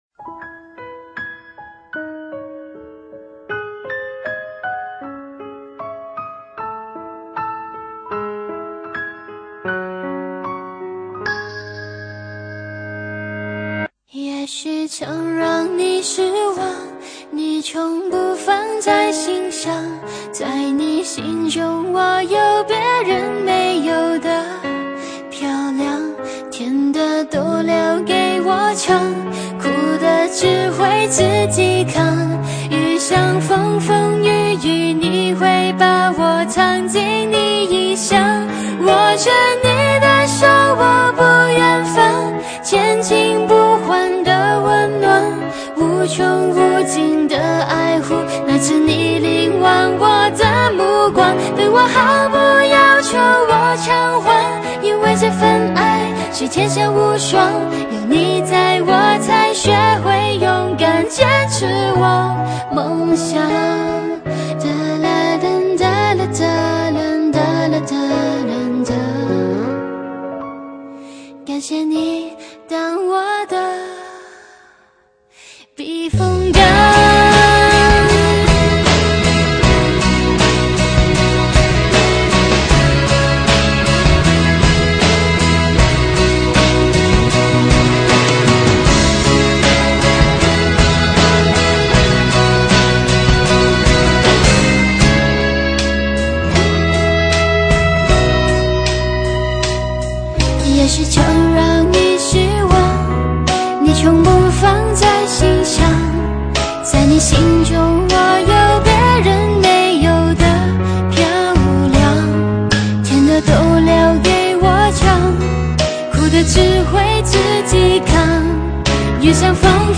性别：女
为什么听了 会变的忧伤